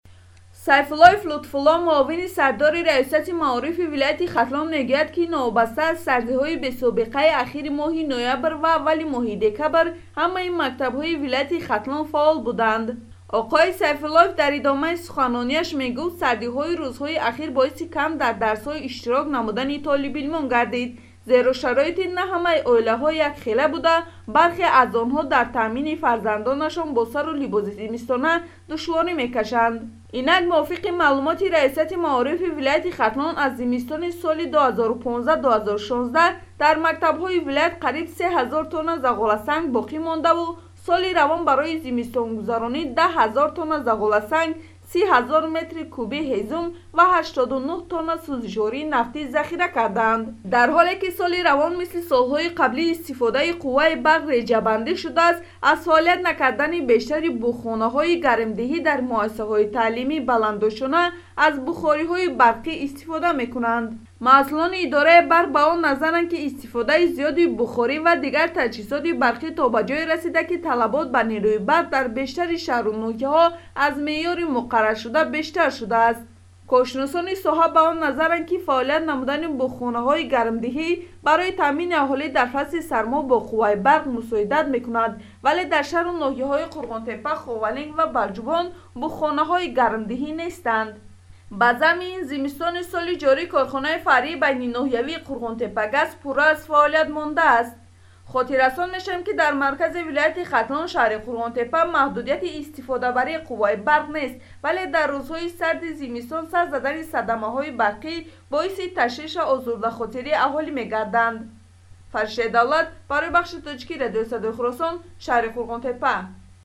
Ин мавзуро дар гузориши ҳамкорамон